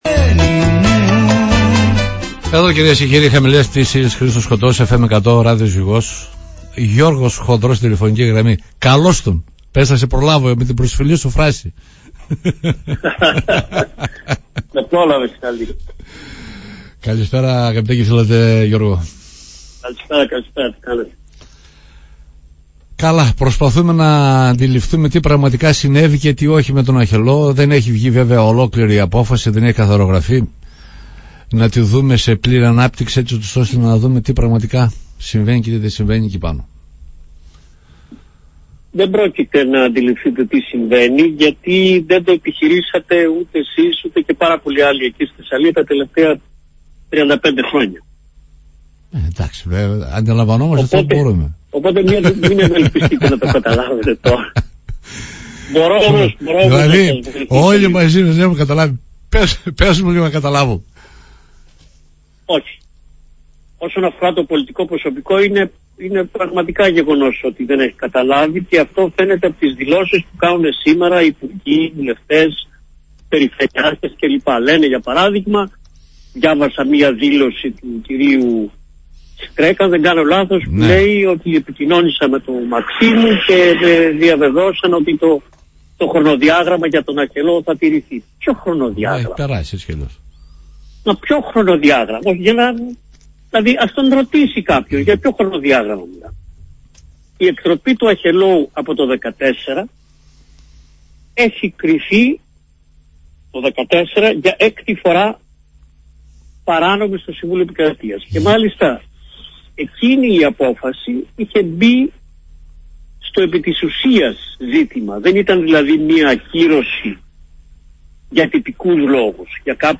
Το γάντι στον Κώστα Αγοραστό πετά ο Γιώργος Χονδρός προκαλώντας τον να απαντήσει τι έκανε ως Περιφερειάρχης από το 2014, όταν ήταν ξεκάθαρη η απόφαση του ΣτΕ για την εκτροπή του Αχελώου, για το υδατικό έλλειμμα στην Θεσσαλία, στην εκπομπή «Χαμηλές Πτήσεις» στην Ζυγό